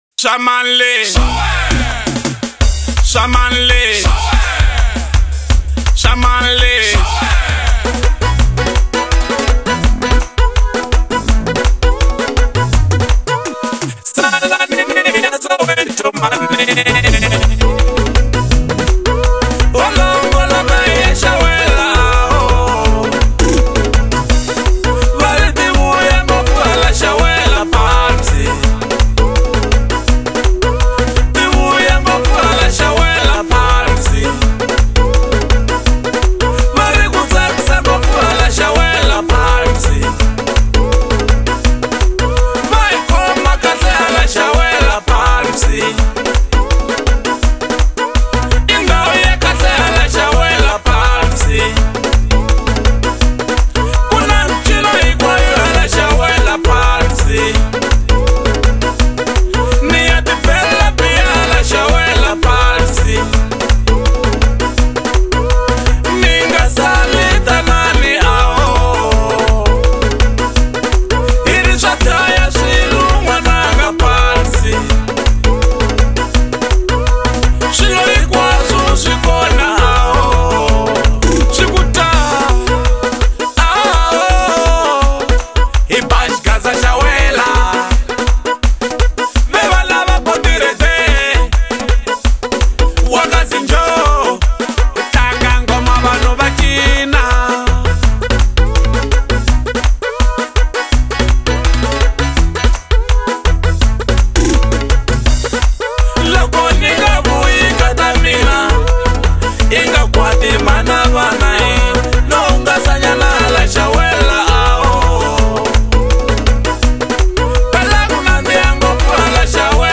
04:27 Genre : Xitsonga Size